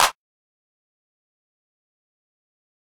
Clap (RightHand).wav